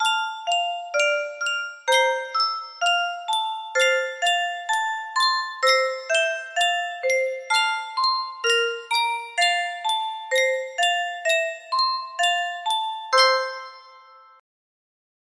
Sankyo Music Box - Fairest Lord Jesus BEN music box melody
Full range 60